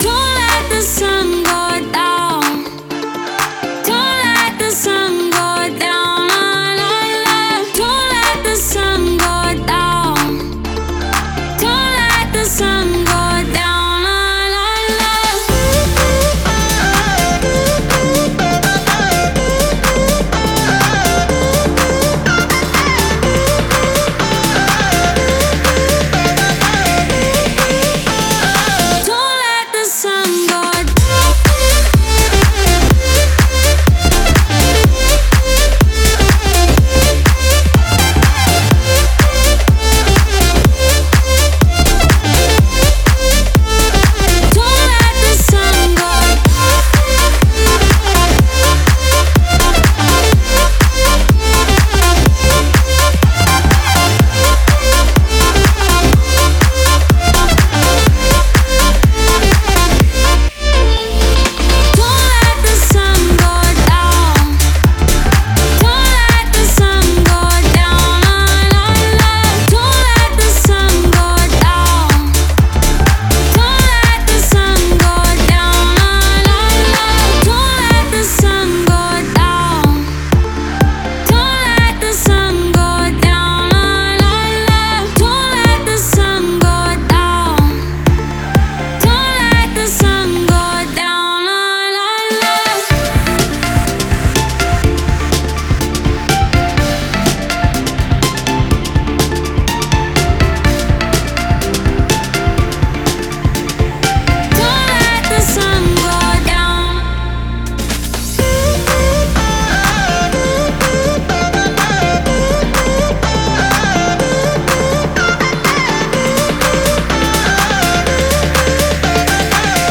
это зажигательная песня в жанре поп